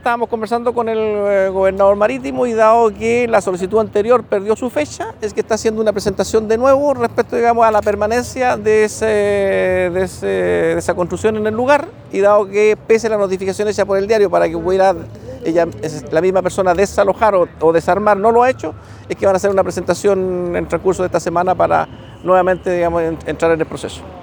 Según dijo el delegado Presidencial de Arauco, Humberto Toro, hubo problemas para notificar a estas personas de la medida judicial.